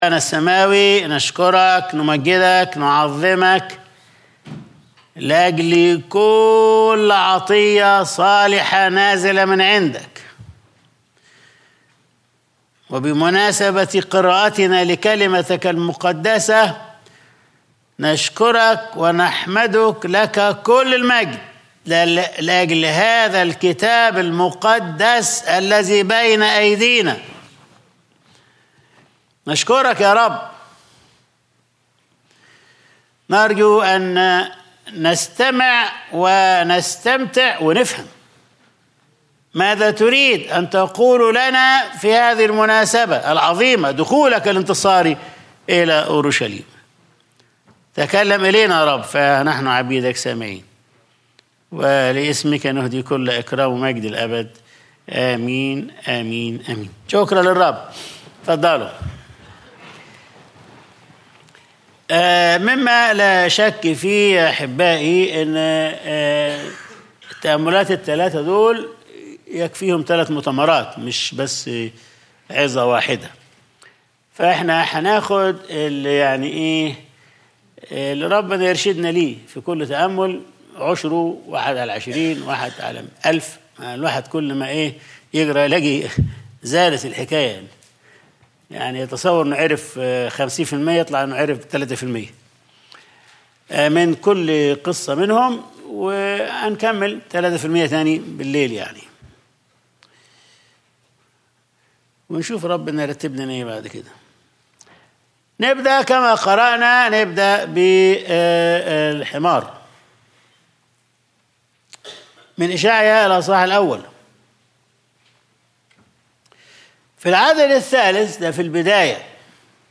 Sunday Service | حمار .. آتان .. جحش